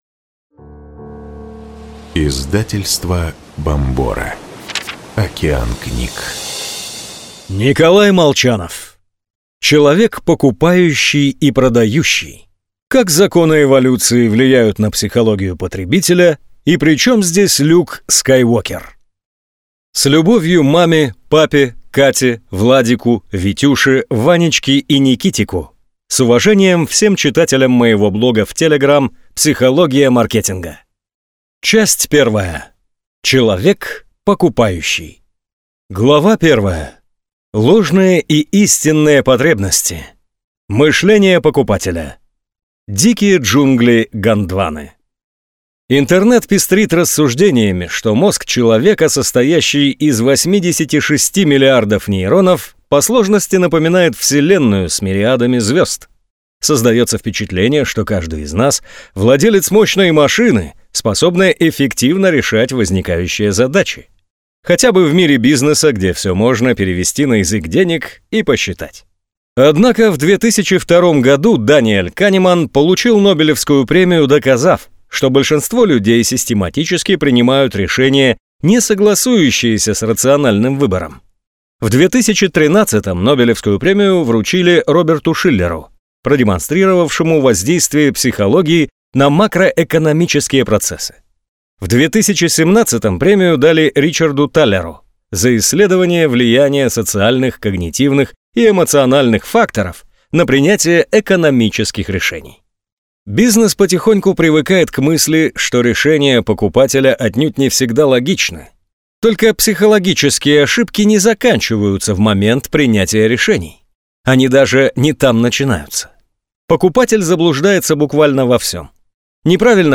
Аудиокнига Человек покупающий и продающий. Как законы эволюции влияют на психологию потребителя и при чем здесь Люк Скайуокер | Библиотека аудиокниг